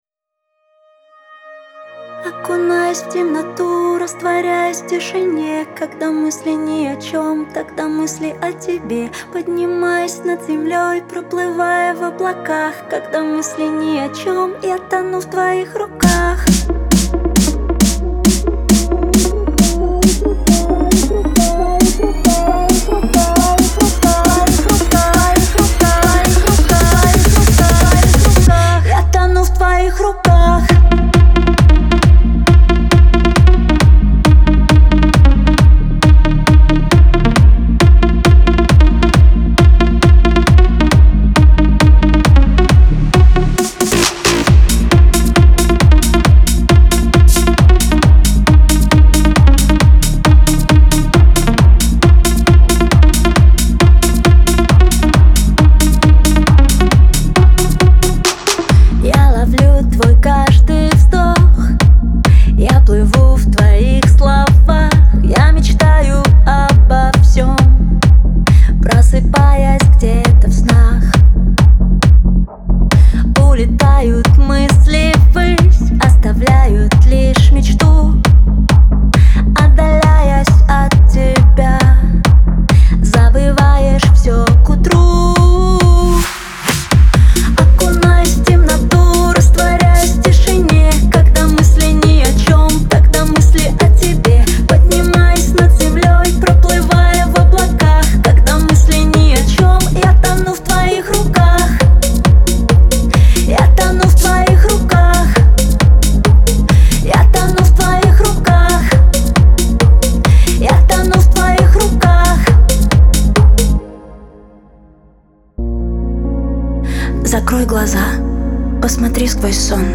эстрада , диско